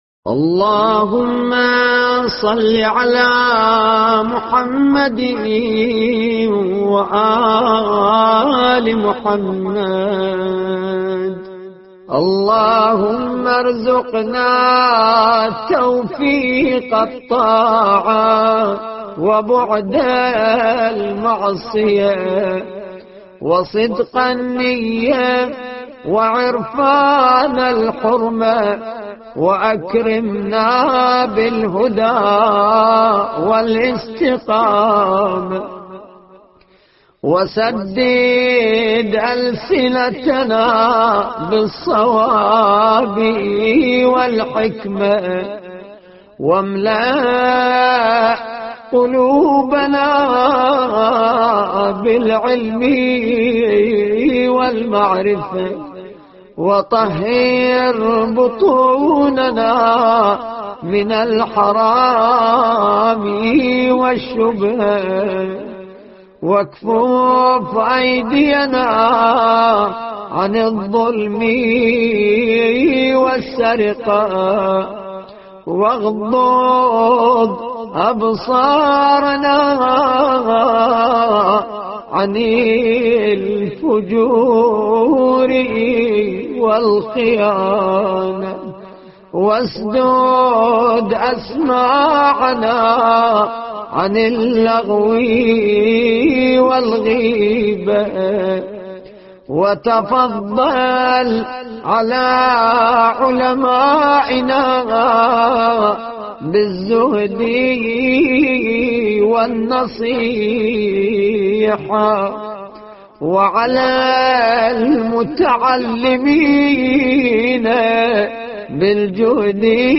اللهم ارزقنا توفيق الطاعة دعاء مروي عن الإمام المهدي